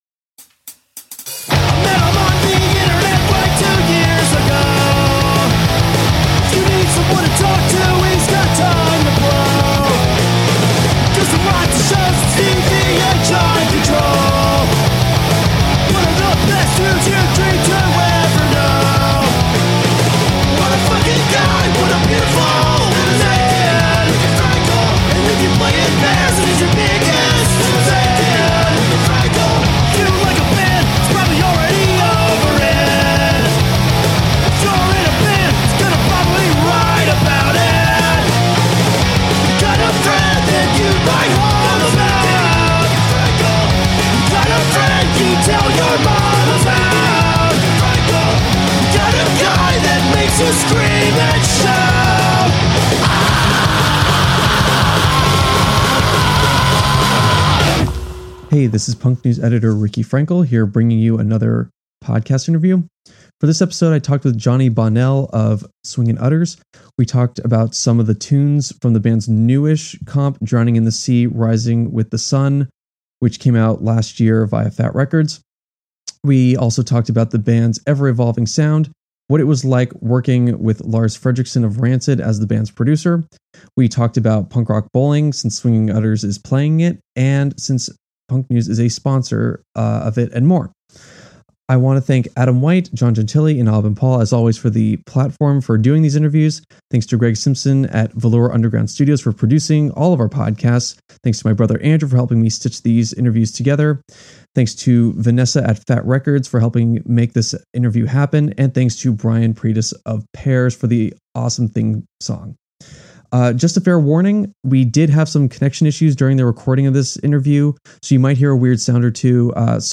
Bonus - Interview with Johnny Bonnel of Swingin' Utters